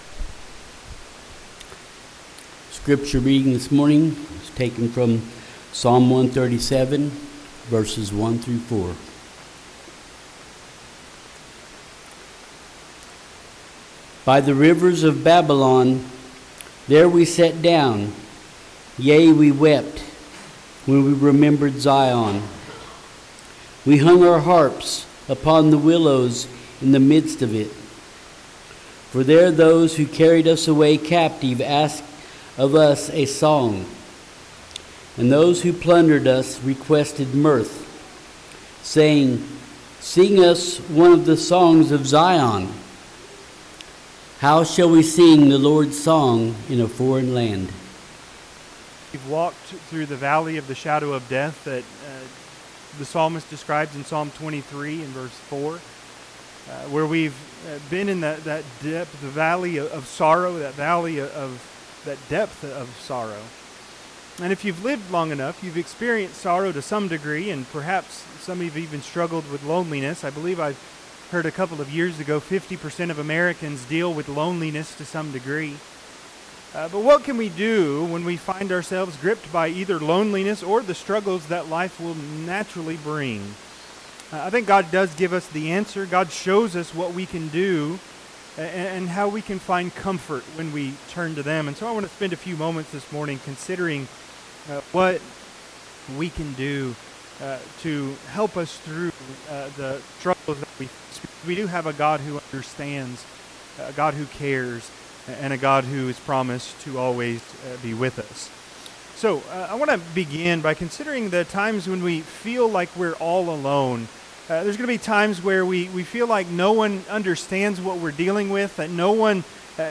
Psalms 137:1-4 Service Type: Sunday AM Topics